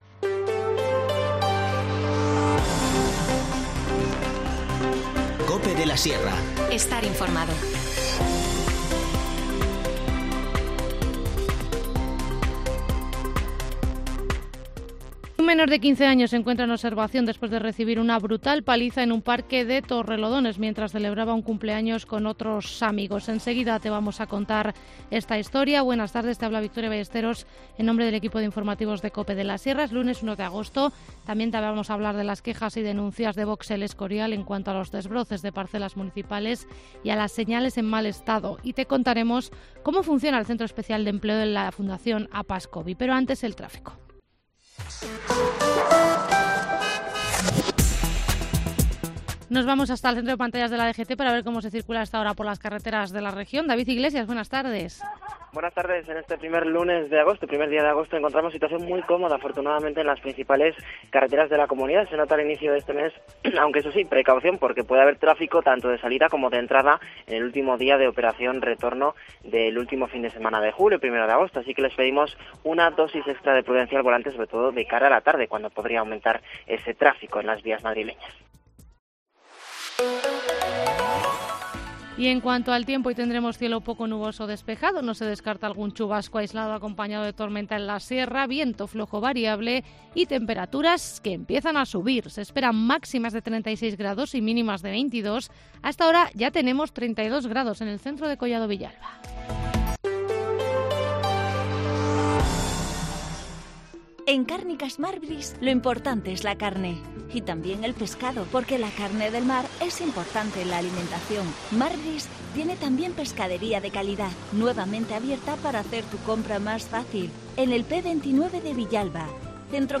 Informativo Mediodía 1 agosto